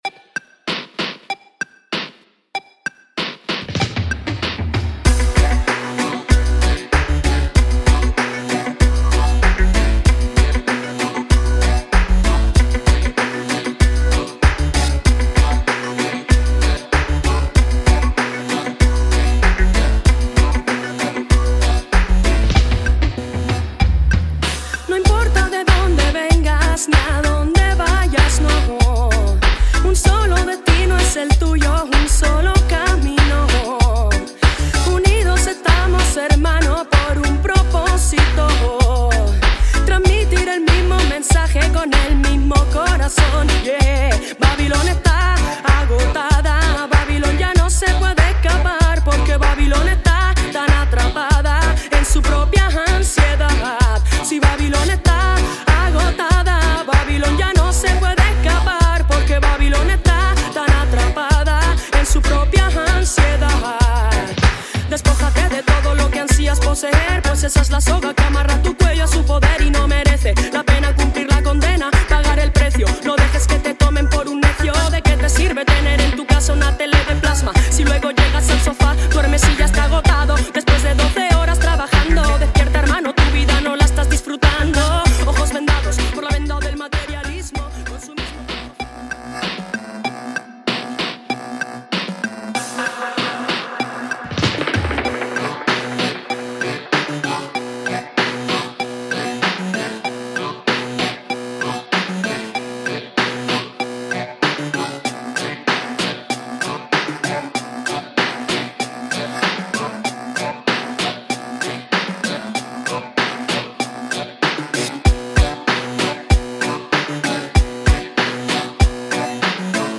New riddim
2 dubs